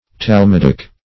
Meaning of talmudic. talmudic synonyms, pronunciation, spelling and more from Free Dictionary.
Search Result for " talmudic" : The Collaborative International Dictionary of English v.0.48: Talmudic \Tal*mud"ic\, Talmudical \Tal*mud"ic*al\, a. [Cf. F. talmudique.]